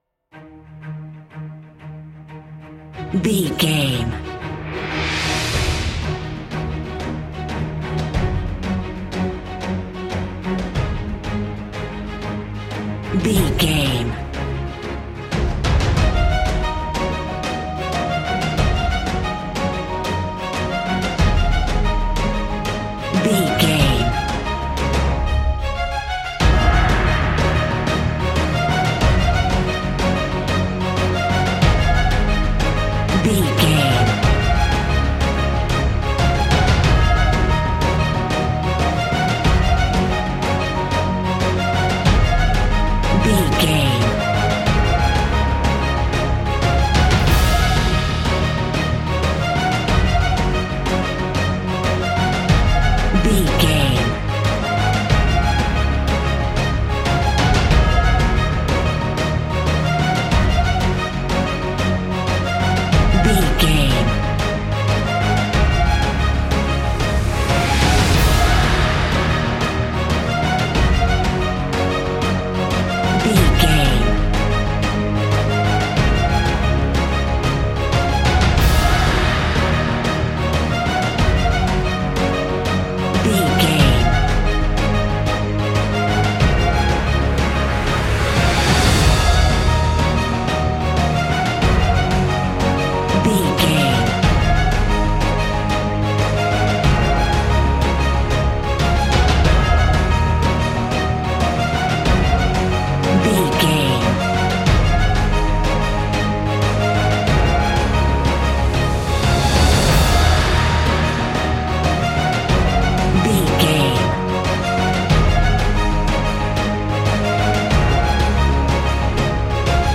Epic / Action
Uplifting
Aeolian/Minor
E♭
heavy
powerful
brass
choir
drums
strings
synthesizer